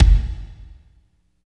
30.01 KICK.wav